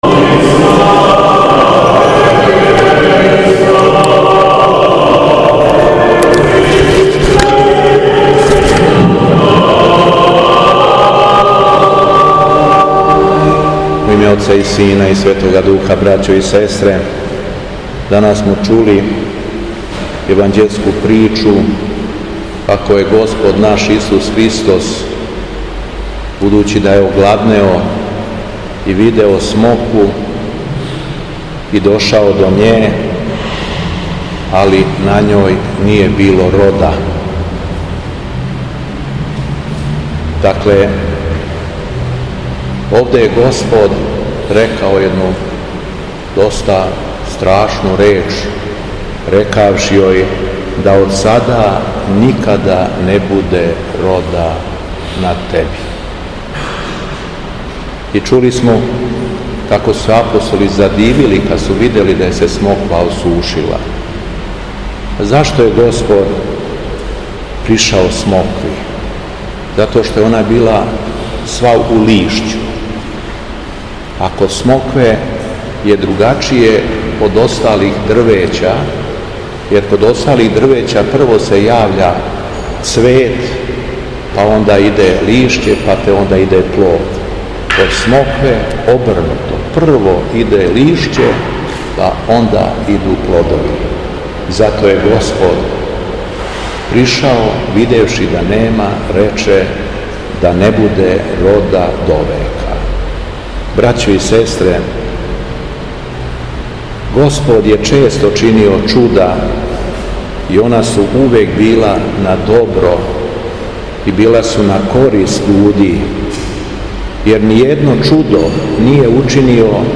Беседа Његовог Високопреосвештенства Митрополита шумадијског г. Јована
У поучној беседи Високопреосвећени се обратио верном народу: